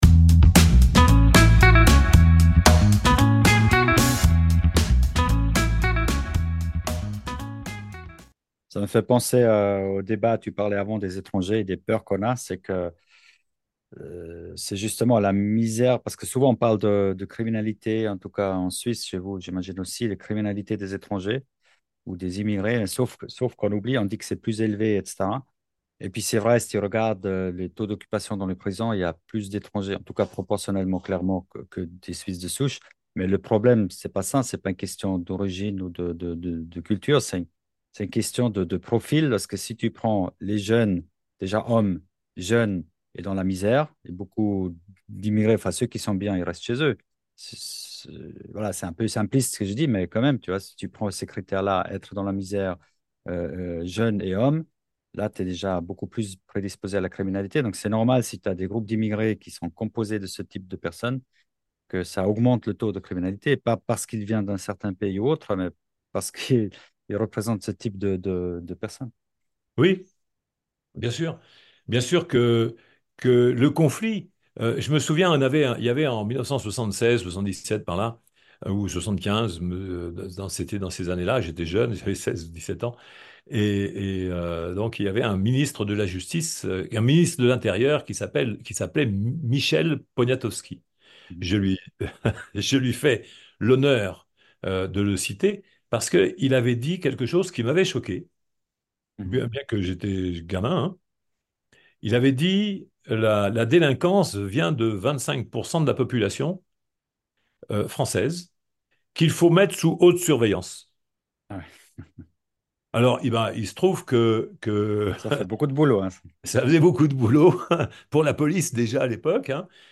Une conversation enrichissante pour comprendre comment la médiation peut transformer les relations humaines et résoudre les conflits de manière efficace. https